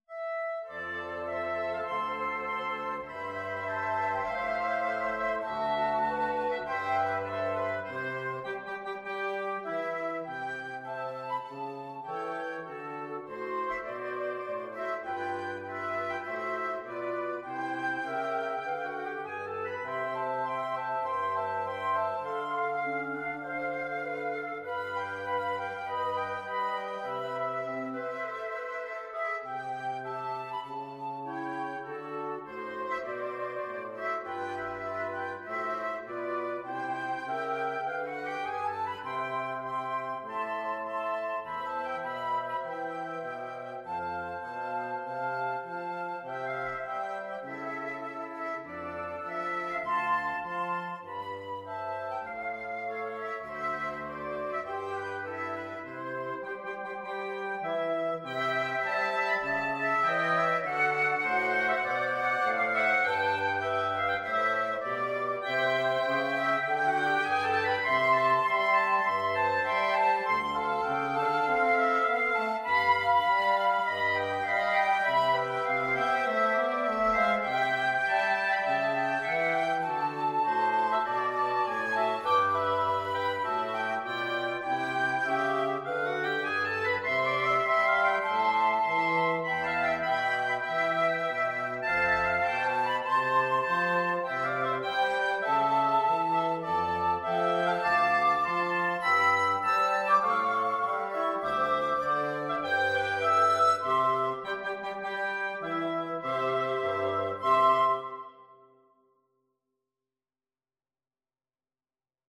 4/4 (View more 4/4 Music)
Moderato = c. 100
Jazz (View more Jazz Wind Quartet Music)
Rock and pop (View more Rock and pop Wind Quartet Music)